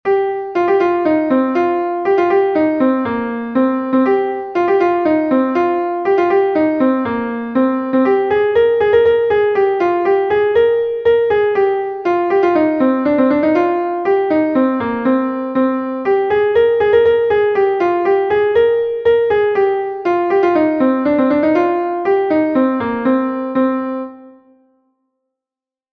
Gavotenn Ploure is a Gavotte from Brittany